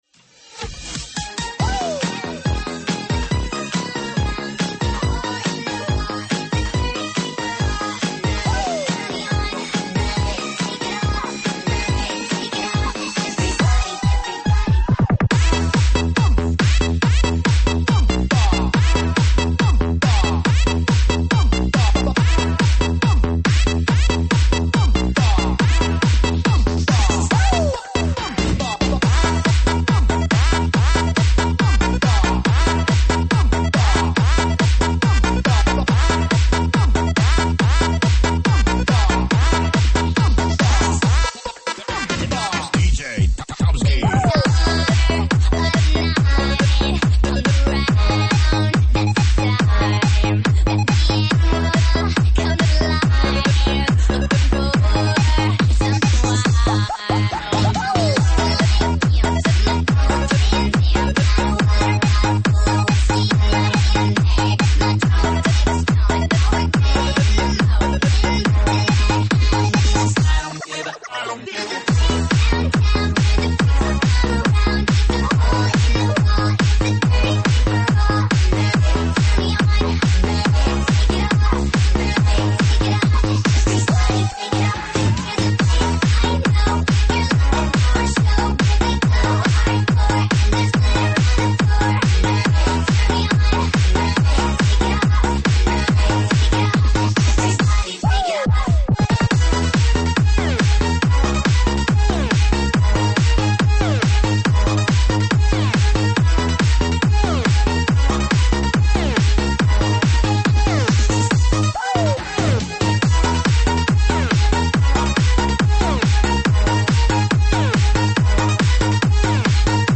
舞曲类别：英文舞曲